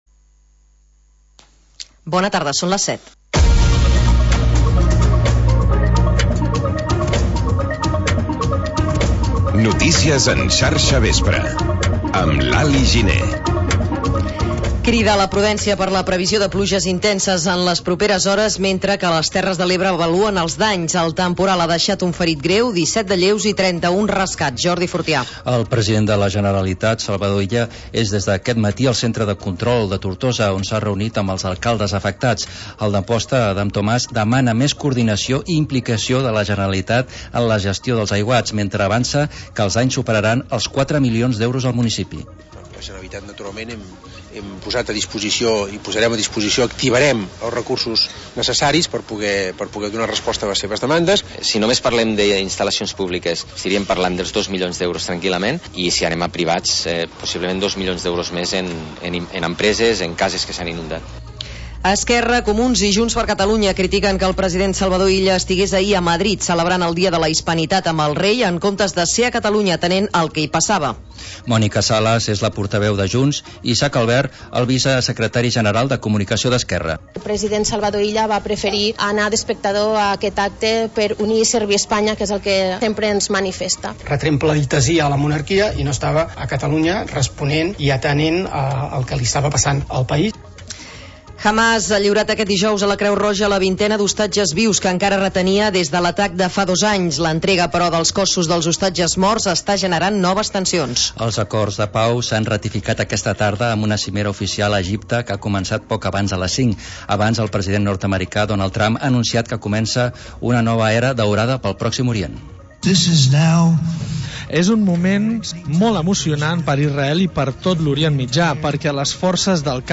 Informatiu que desplega una mirada àmplia sobre el territori, incorporant la informació de proximitat al relat de la jornada.